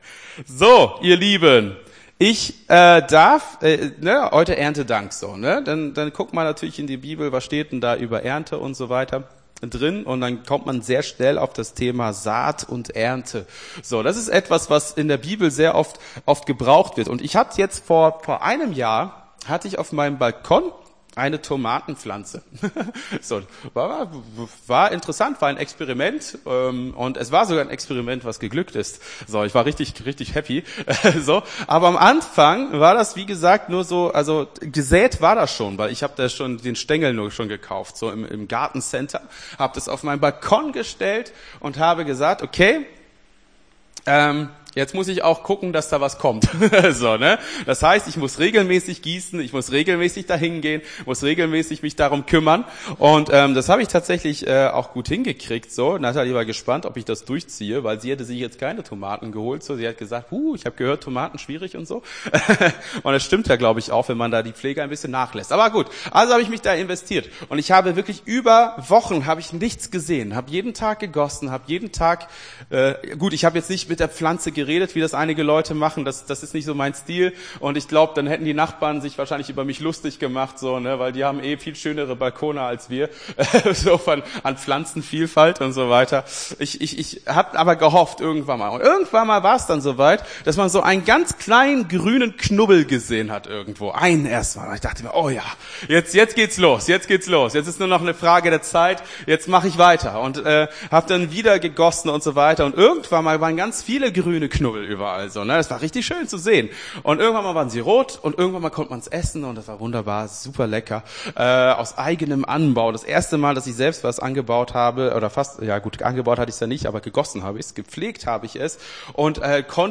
Gottesdienst 01.10.23 - FCG Hagen